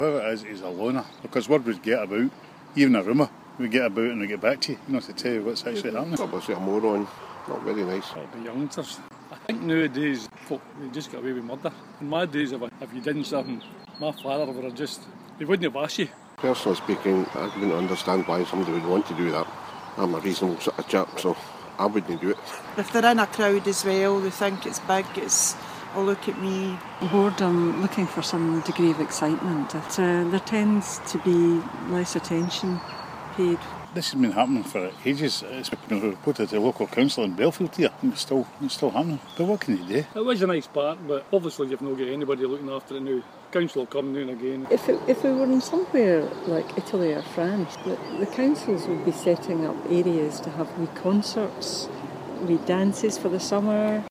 We speak to dog walkers about the fires in the Bellfield Estate